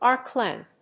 árc làmp [lìght]